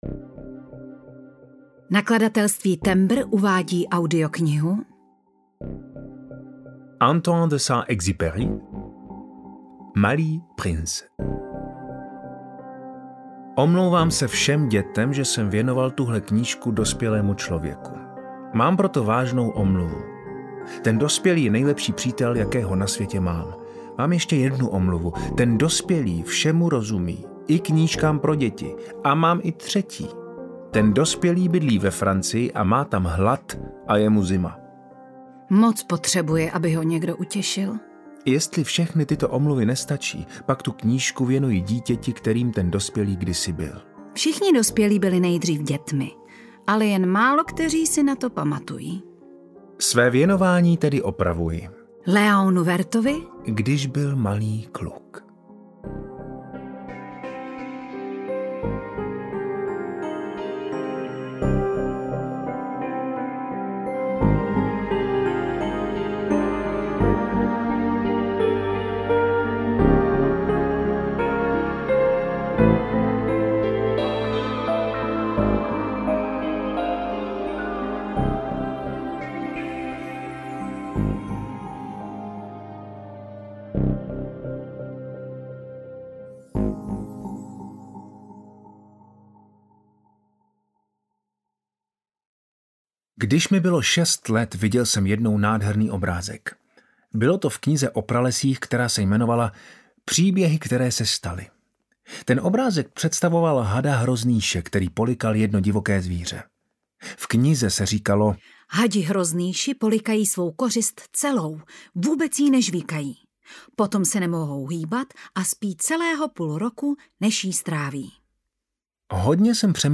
Malý Princ audiokniha
Ukázka z knihy